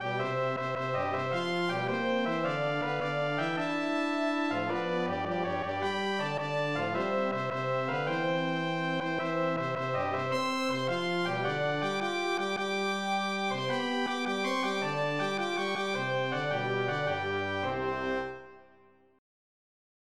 ADMIRE's output is sent back across the MIDI interface to a synthesizer for playback.
While the harmonies do not match the traditional Western harmonies for the pieces, they are still consonant.